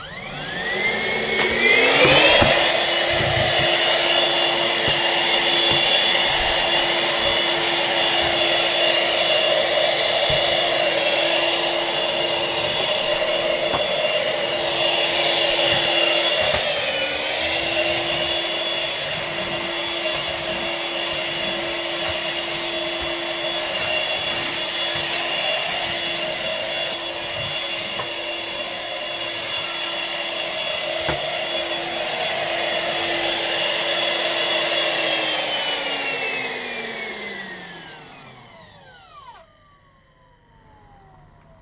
悩んでいる様子をイメージしたシンプルで使いやすいBGM。